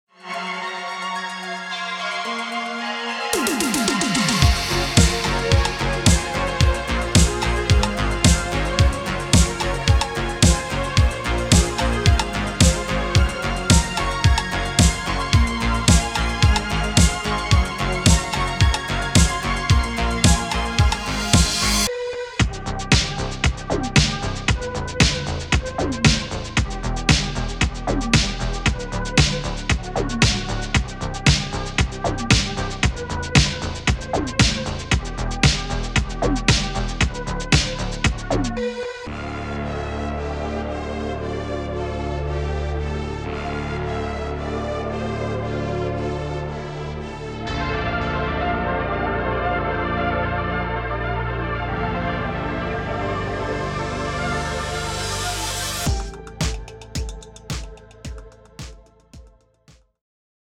Synthwave